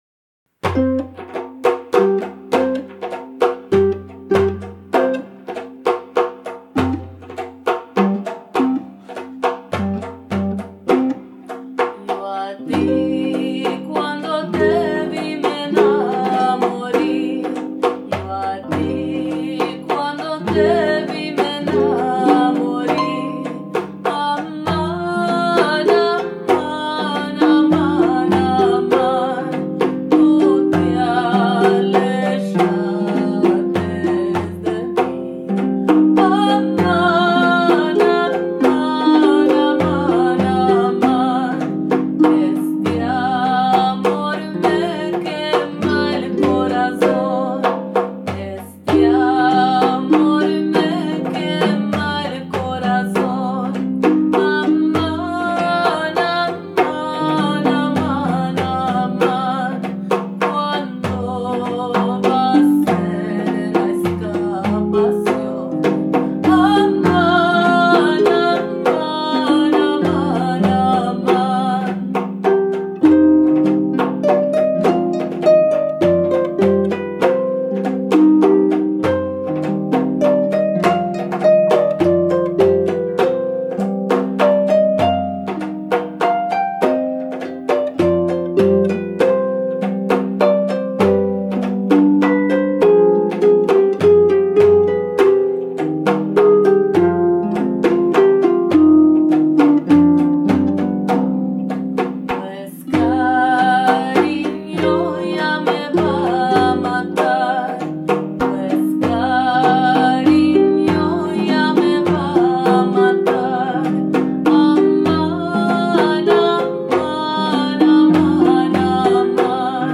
The final melody is in the Andalusian poetic form of the muwashshah.
The song has since been performed several times in Los Angeles.
voice
kaval
darbuka
oud